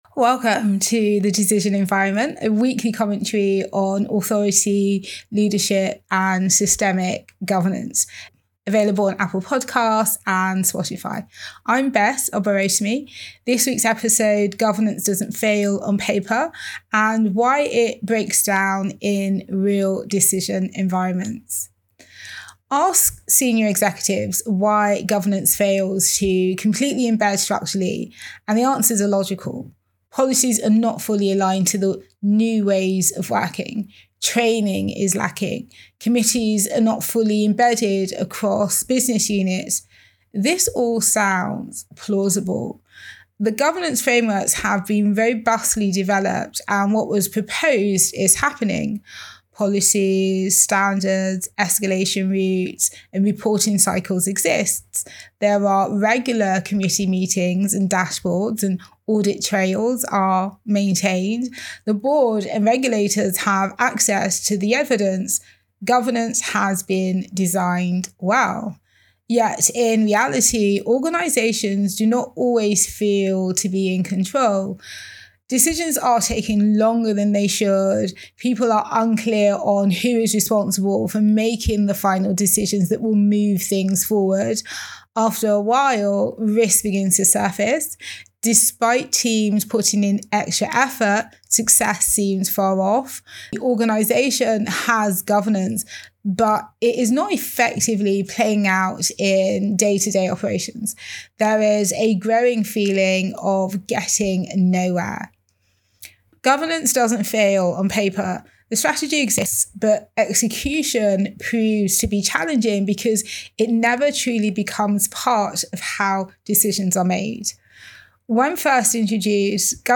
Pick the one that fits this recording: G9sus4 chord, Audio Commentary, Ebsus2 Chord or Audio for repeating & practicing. Audio Commentary